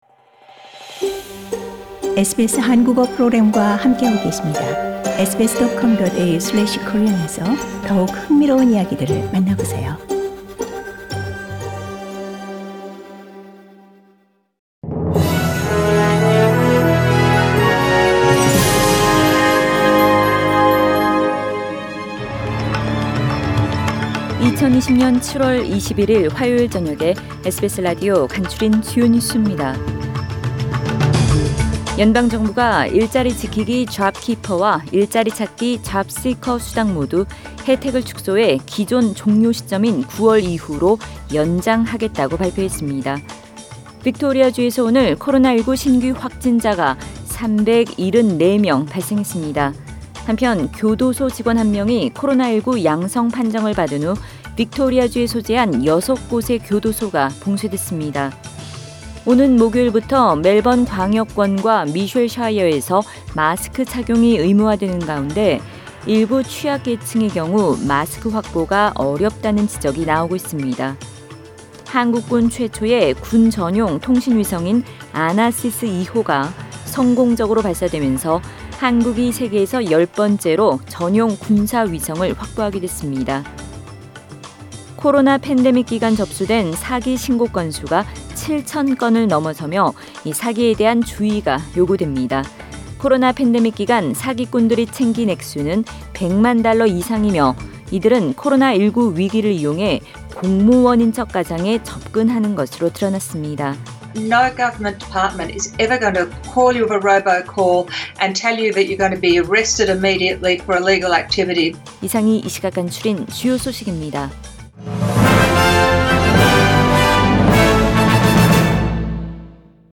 2020년 7월 21일 화요일 저녁의 SBS Radio 한국어 뉴스 간추린 주요 소식을 팟 캐스트를 통해 접하시기 바랍니다.